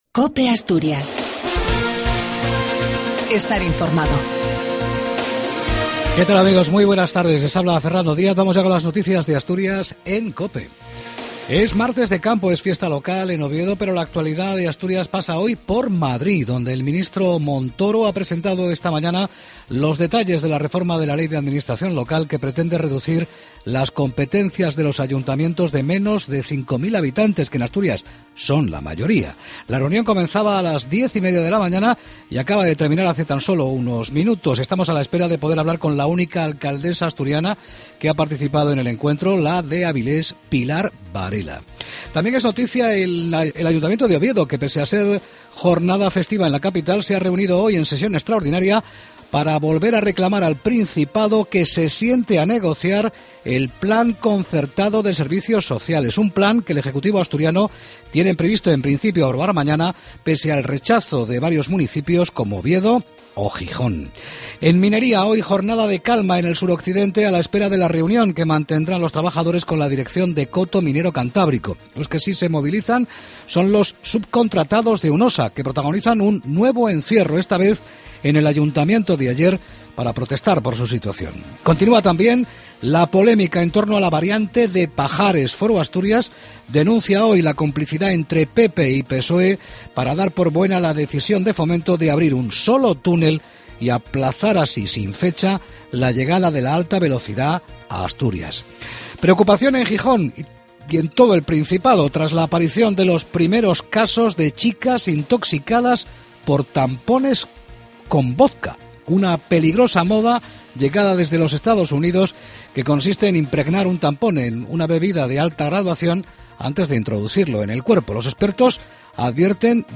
AUDIO: LAS NOTICIAS DE ASTURIAS AL MEDIODIA.